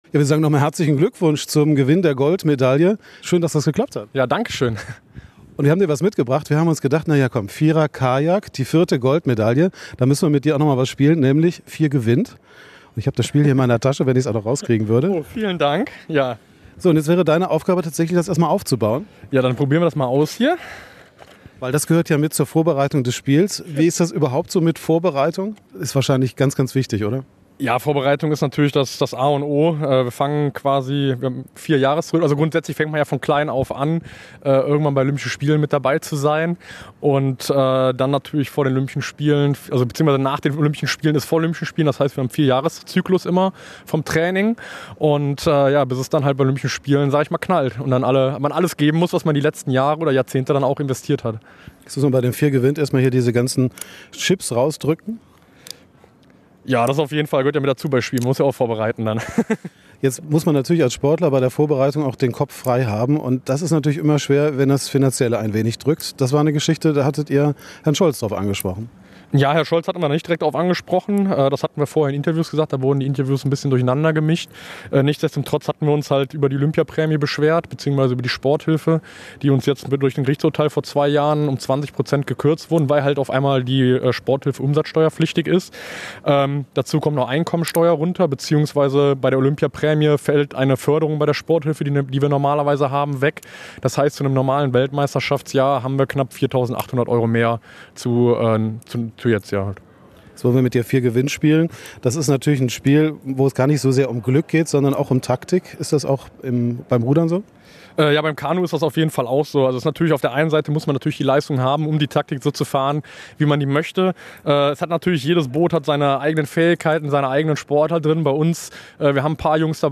Olympionike zurück in Essen: Reporter tritt gegen Goldmedaillen-Gewinner an - Radio Essen